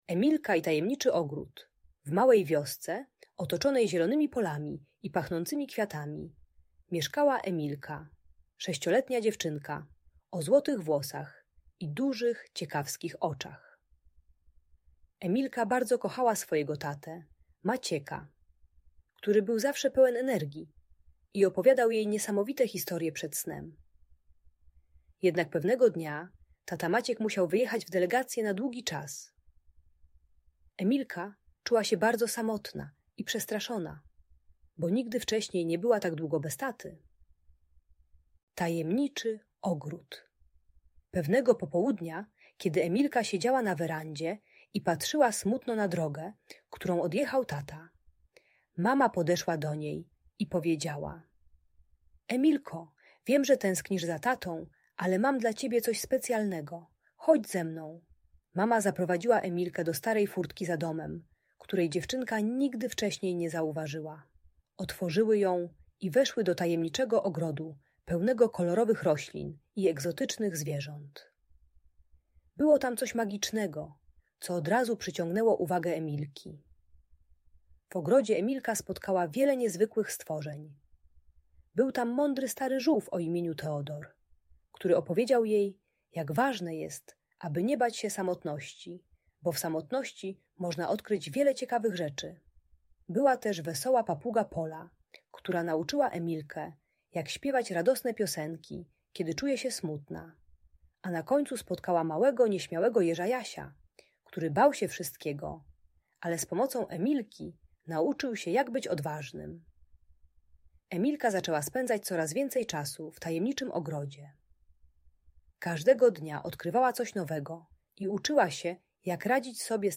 Emilka i Tajemniczy Ogród - Audiobajka